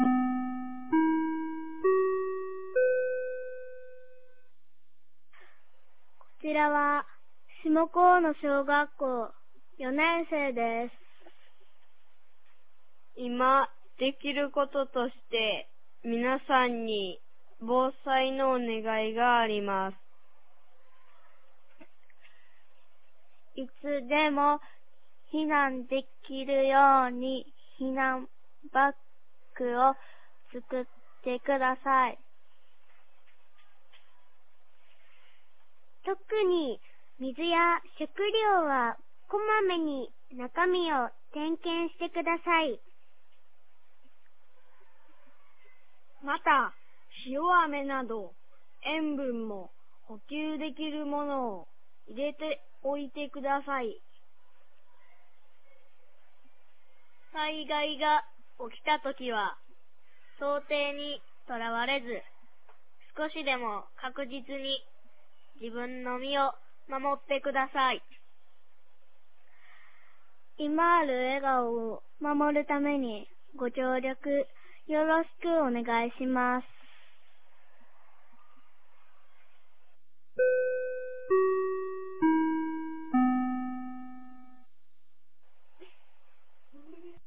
2024年02月09日 15時31分に、紀美野町より全地区へ放送がありました。